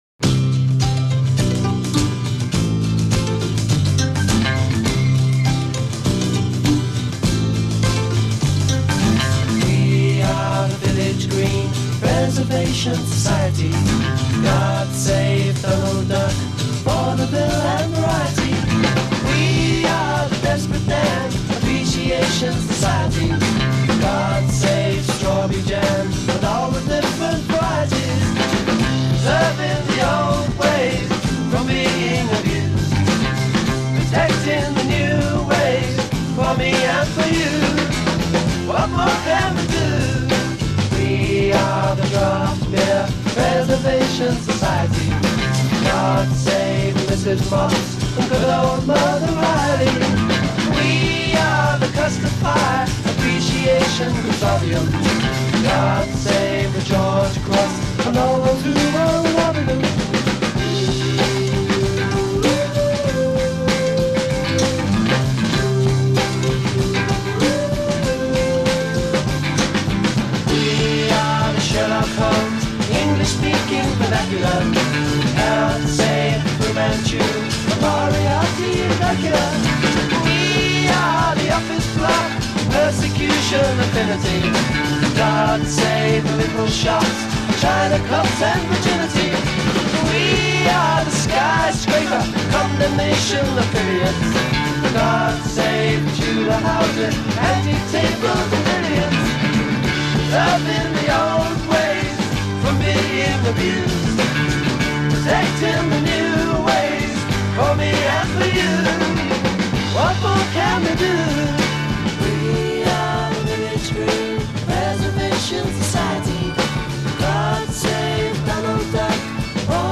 " Two-part vocal harmonies. a
Verse a 4 + 4 modulation up a whole tone to create variety f
Verse a 4 + 4 backing vocals take the lead a
Coda 4 + 4+ fade on verse chord sequence i